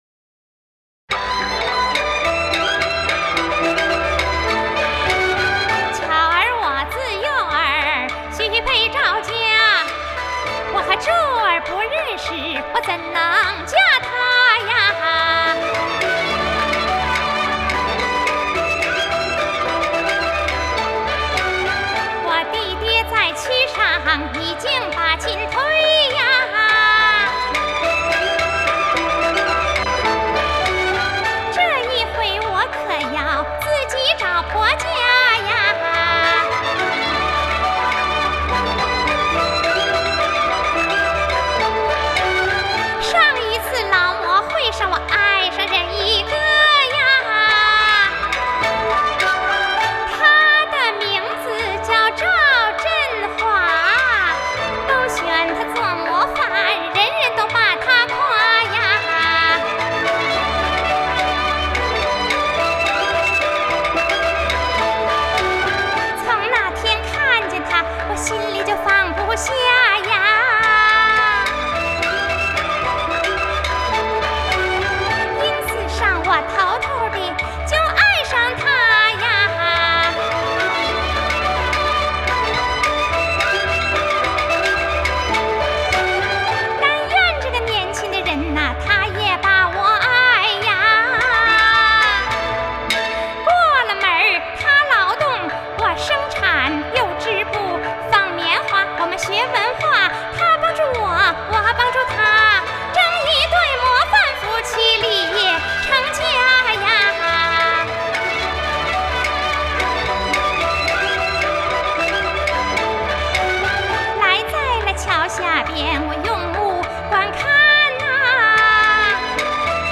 越剧